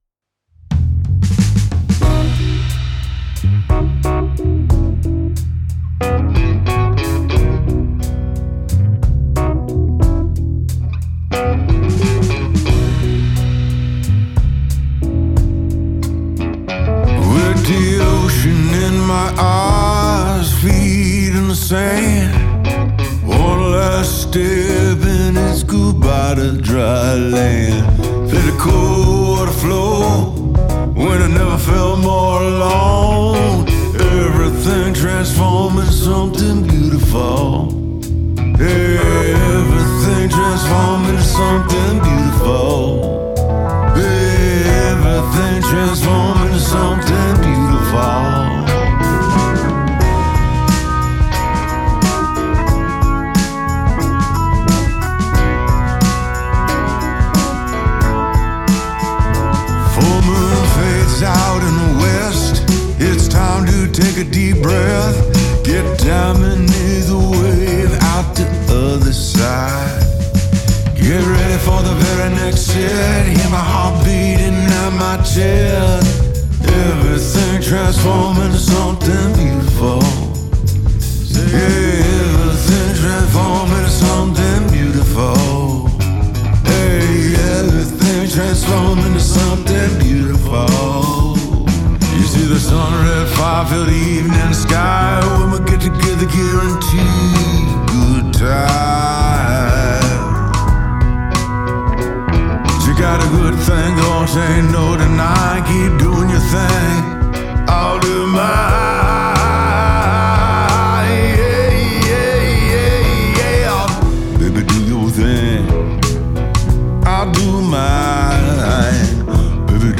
Genre: Rock, Singer.